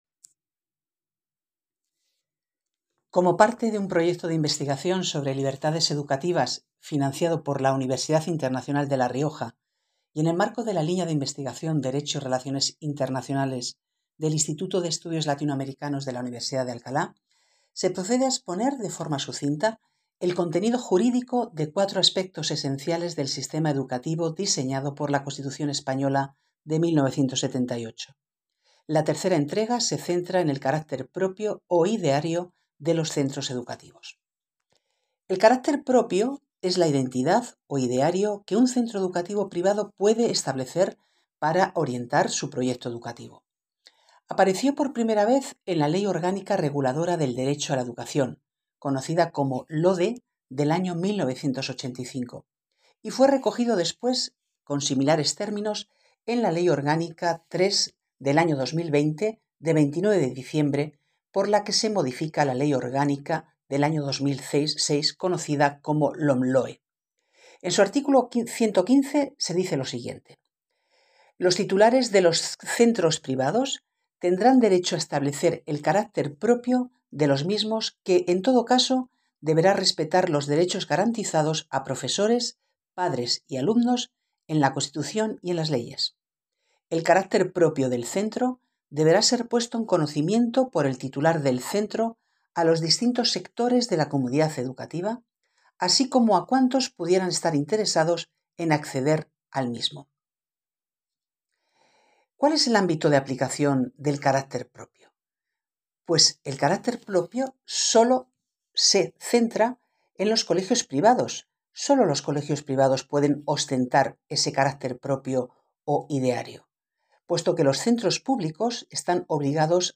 Presentación